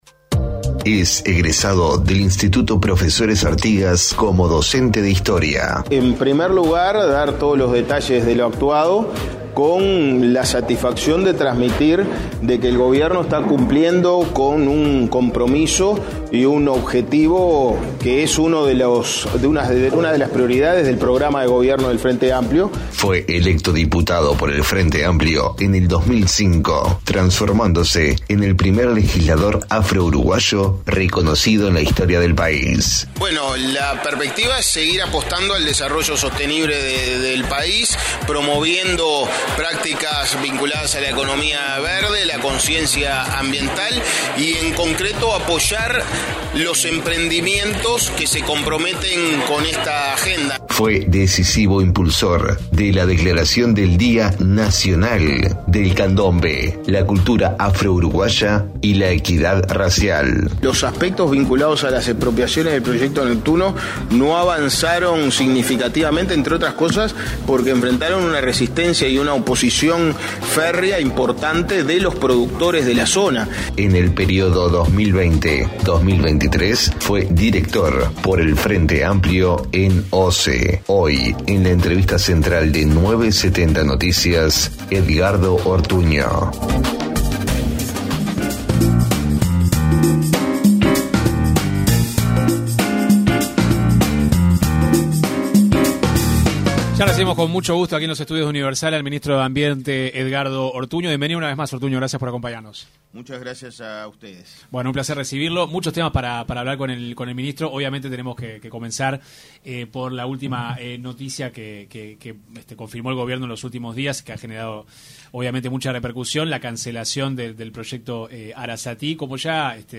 Escuche la entrevista completa aquí: El ministro de Ambiente, Edgardo Ortuño, se refirió en una entrevista con 970 Noticias, a la tala de monte nativo que se estudia si se tiene que realizar o no, para construir la nueva represa en Casupá.